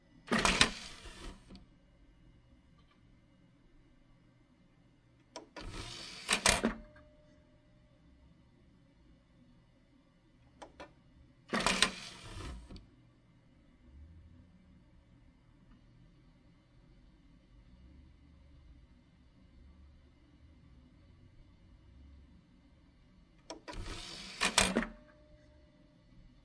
Cd rom的声音 " 从cd rom中提取cd
描述：这是从CD ROM获取的CD的声音。使用iPhone SE录制并使用GoldWave进行编辑。